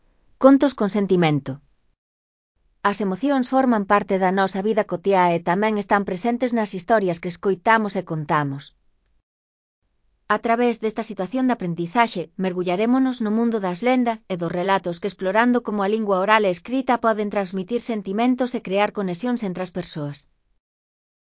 Elaboración propia (proxecto cREAgal) con apoio de IA voz sintética xerada co modelo Celtia.